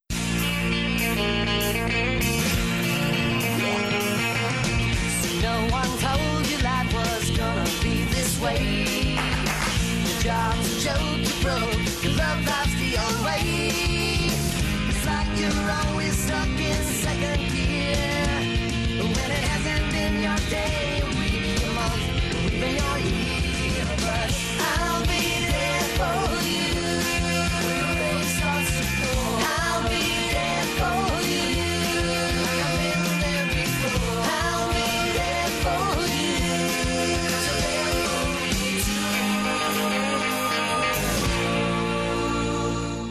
Theme Song (WAV 7.7 MB)